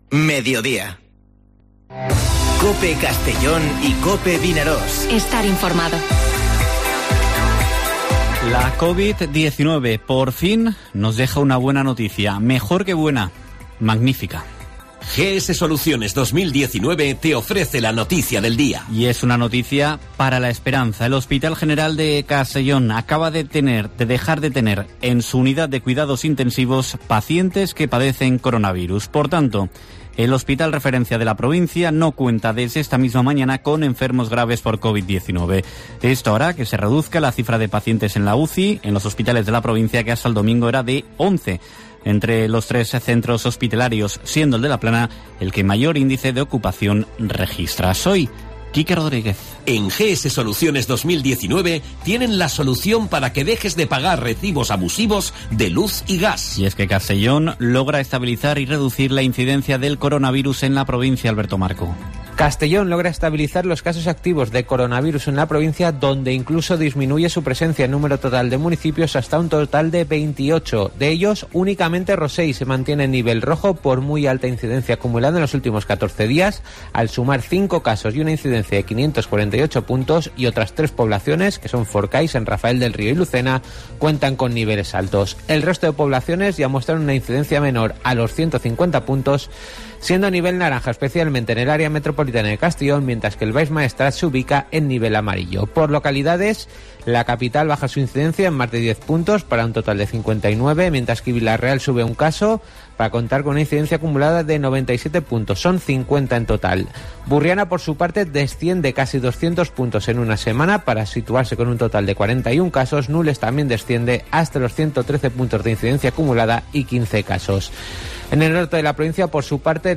Boletines COPE